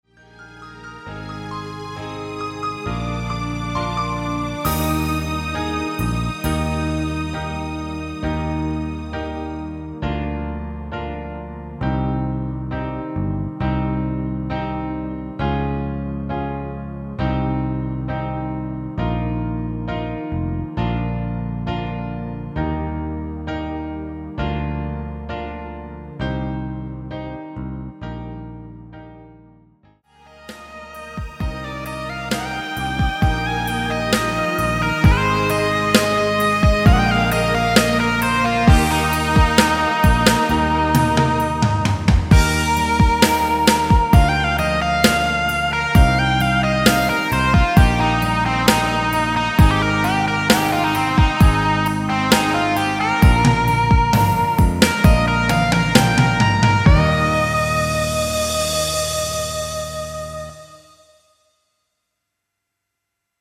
엔딩이 페이드 아웃이라 라이브 하시기 좋게 엔딩을 만들어 놓았습니다.
Eb
앞부분30초, 뒷부분30초씩 편집해서 올려 드리고 있습니다.